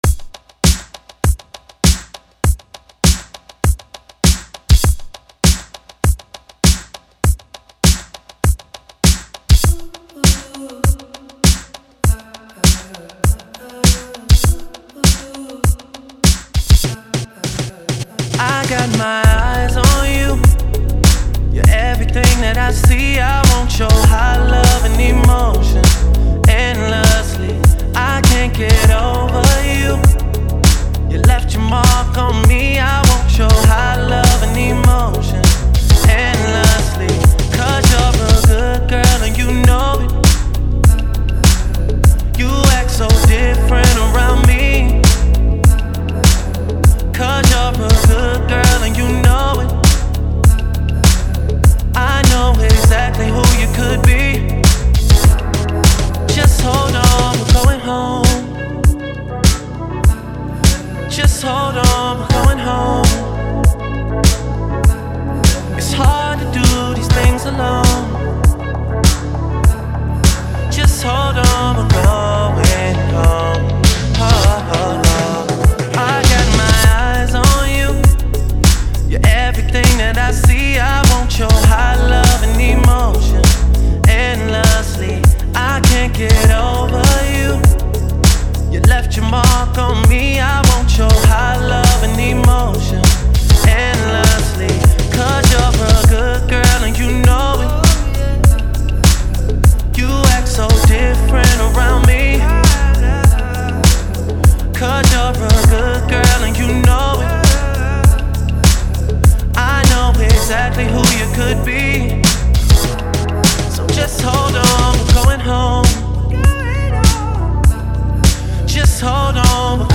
last call ballad